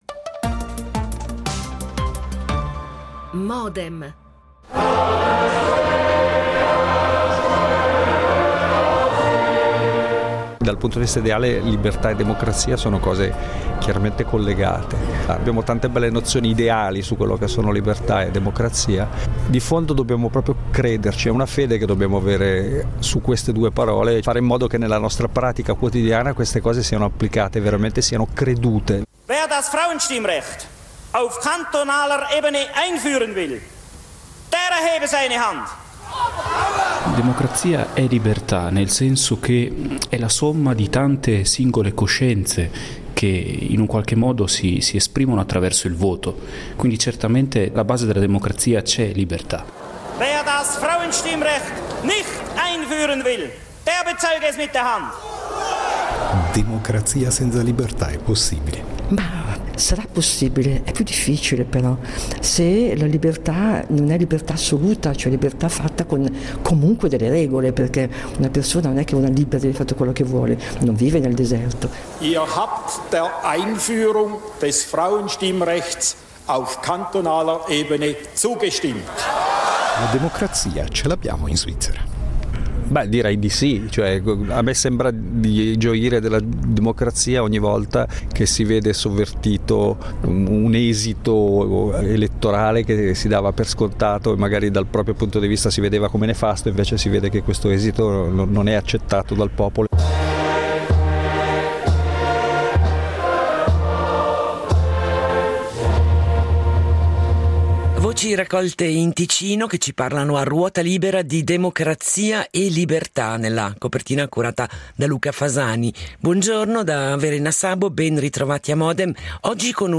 Modem ha preso spunto dalla pubblicazione del libro “ Democrazia e libertà ” di Adriano Cavadini per moderarne la presentazione pubblica al Centro svizzero di Milano. E vi propone una sintesi della discussione che ne è scaturita.